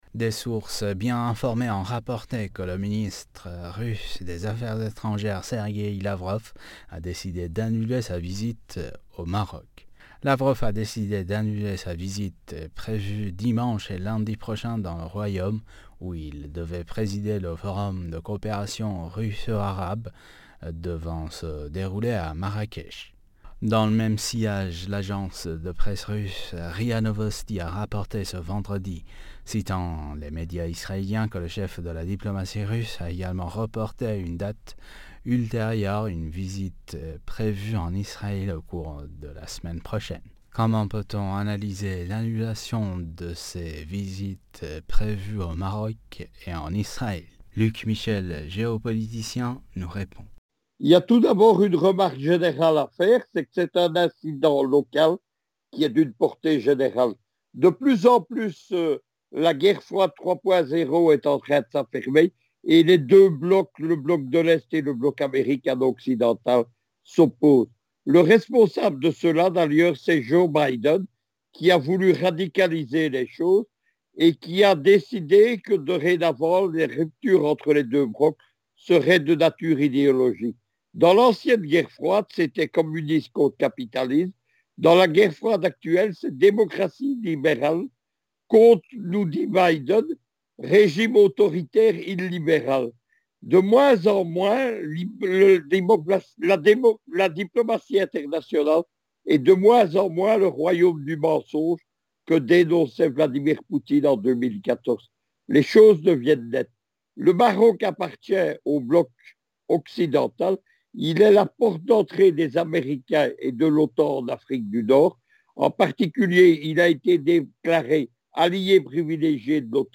géopoliticien nous répond.